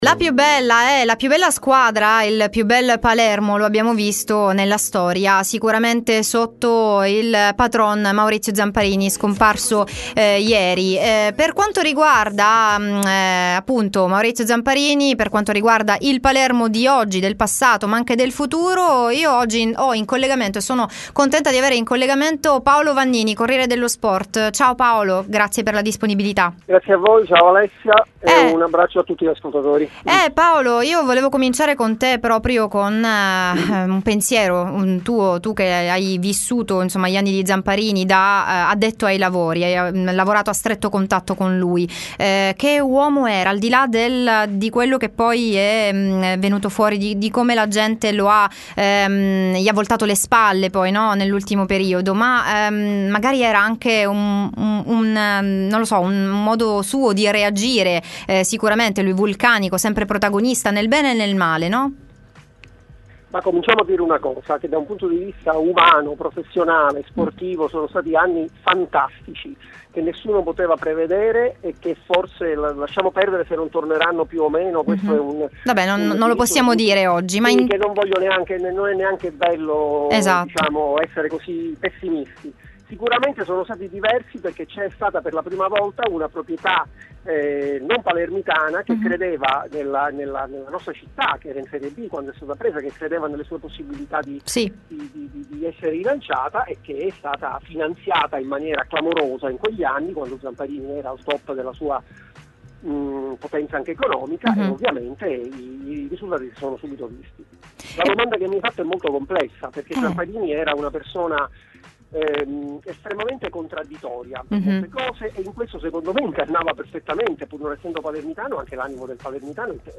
Time Sport intervista